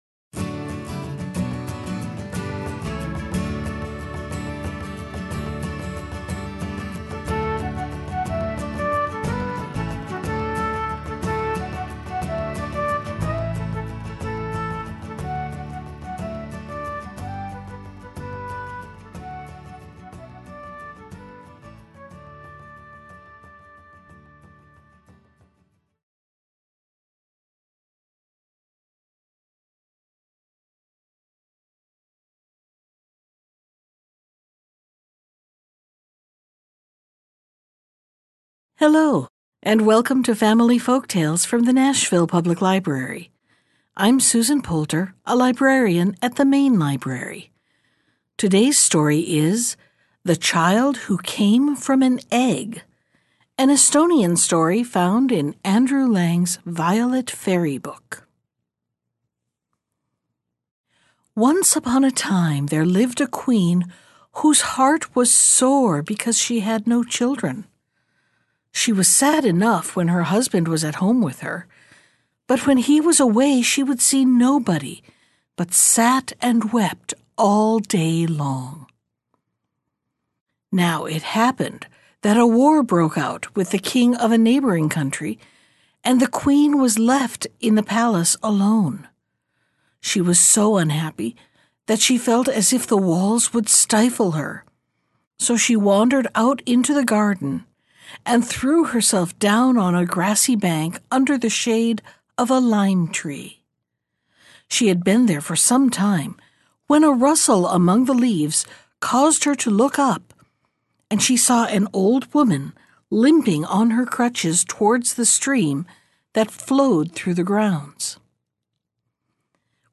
Bedtime Stories